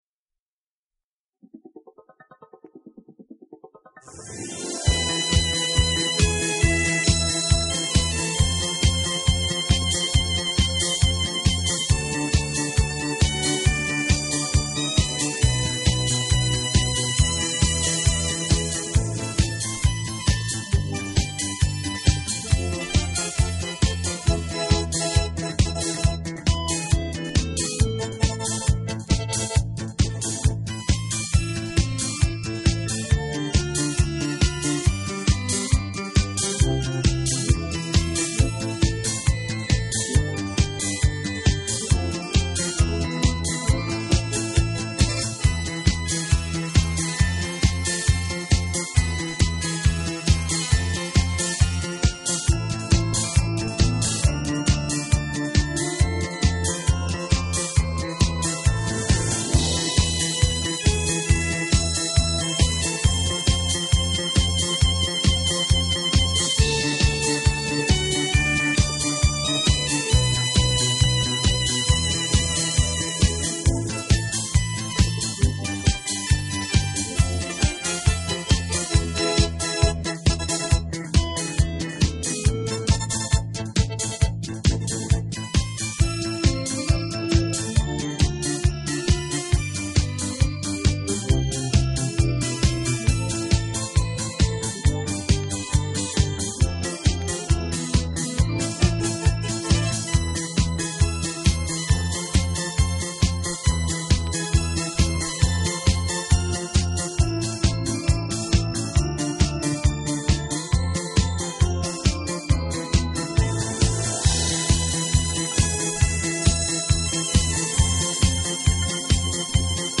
唱片介质：金碟黑胶